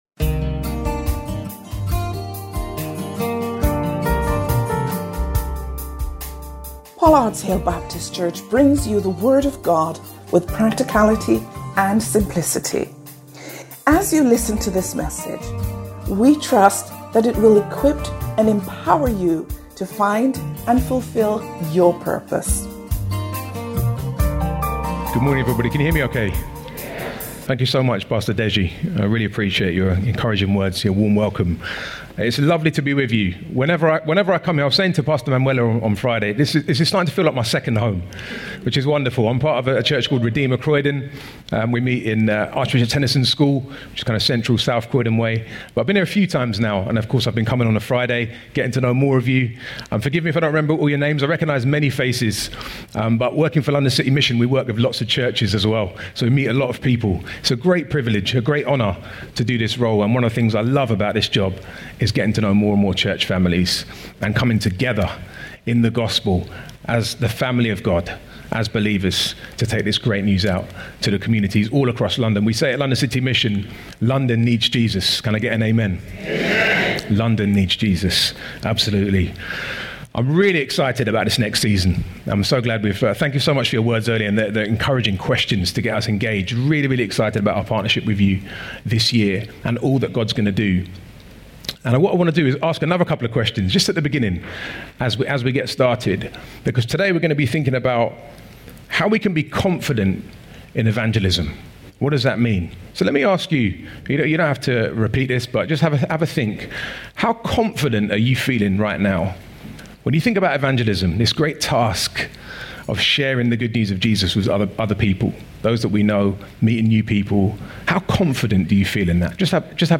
Watch this service and more on our YouTube channel – CLICK HERE